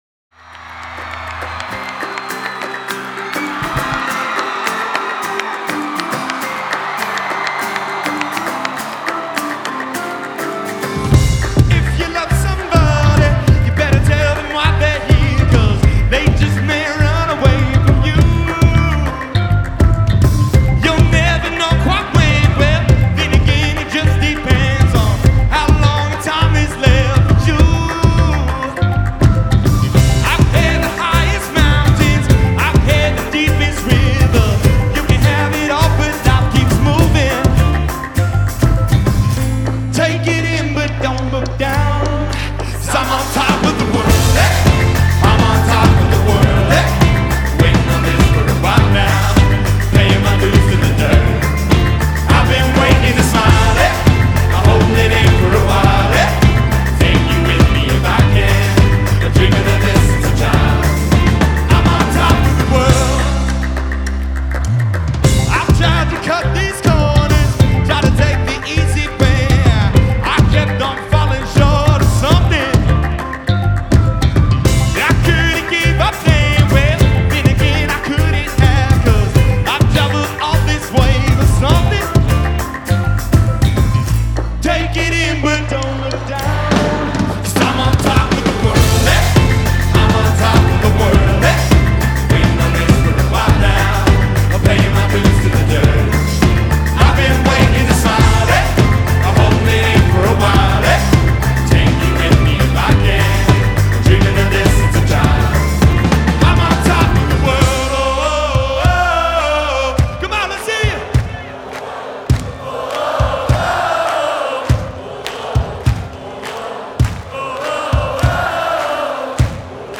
Genre : Alternative & Indie
Live From Red Rocks